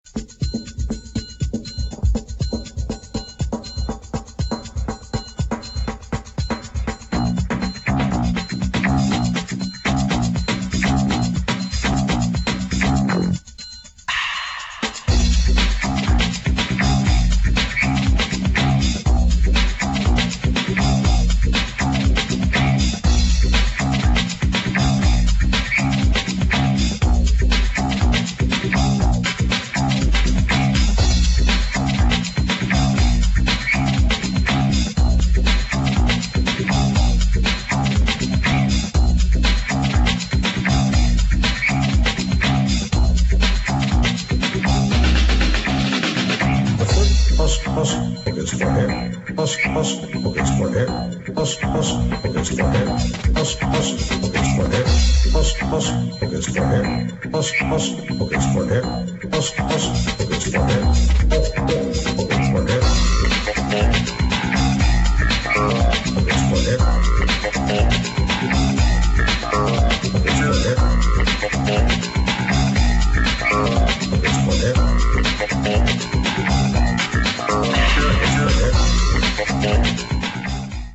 [ BREAKBEAT | DOWNBEAT ]